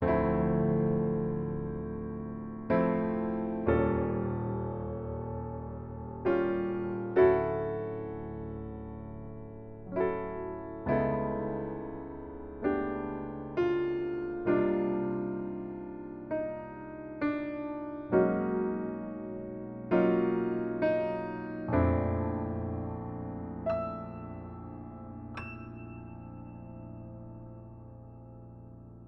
描述：8小节阴郁的夜间音乐，用钢琴演奏。
标签： 66 bpm Jazz Loops Piano Loops 4.90 MB wav Key : Unknown
声道立体声